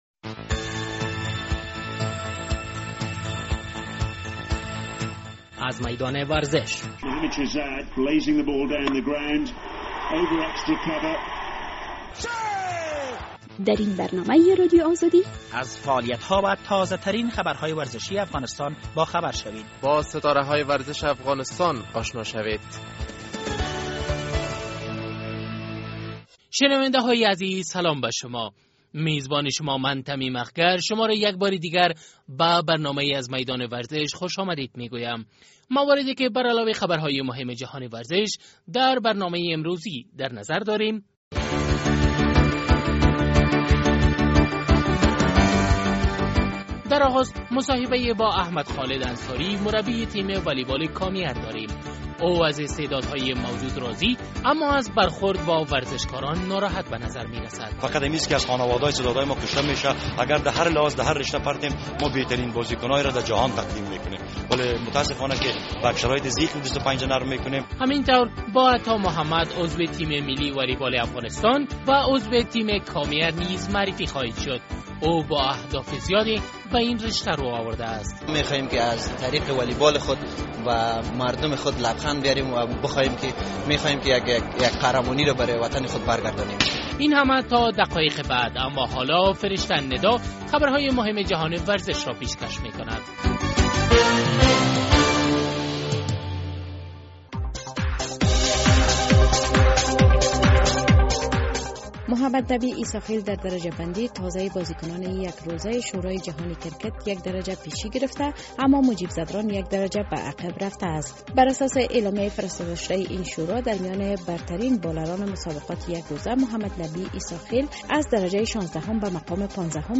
برنامۀ ورزش
مصاحبه